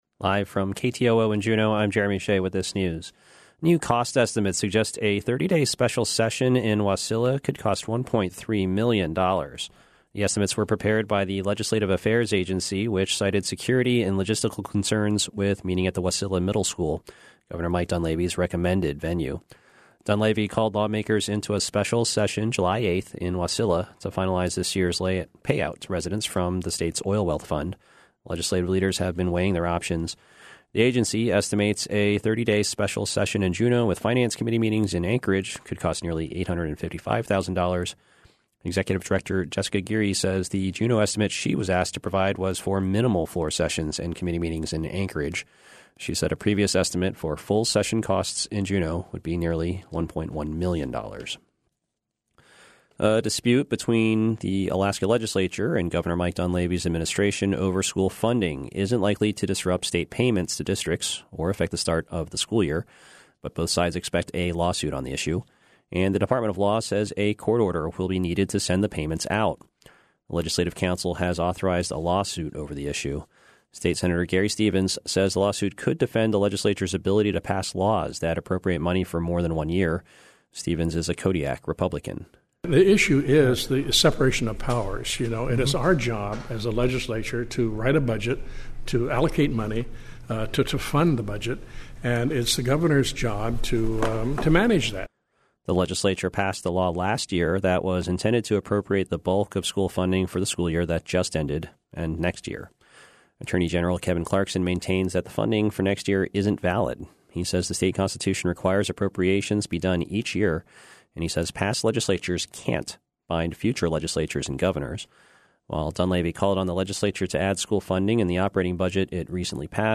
Newscast – Friday, June 21, 2019